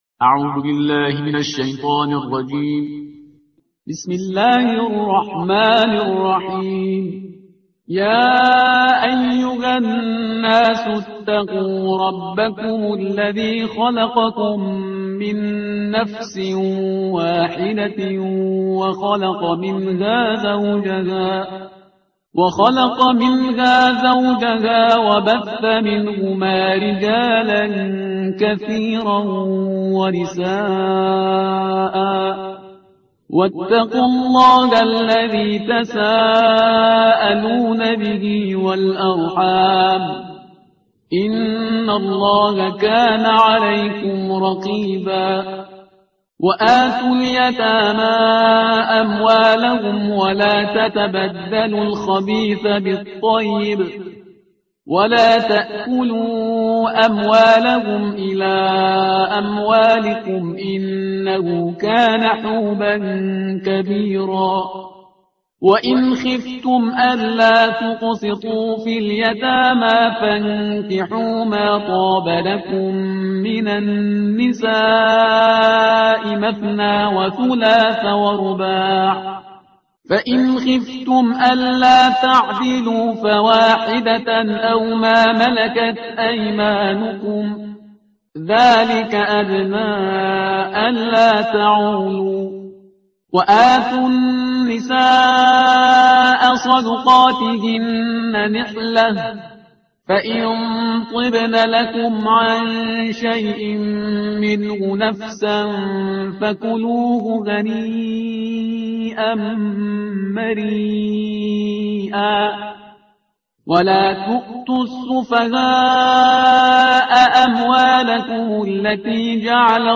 ترتیل صفحه 77 قرآن با صدای شهریار پرهیزگار
Parhizgar-Shahriar-Juz-04-Page-077.mp3